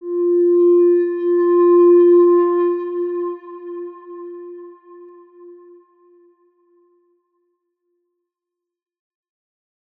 X_Windwistle-F3-mf.wav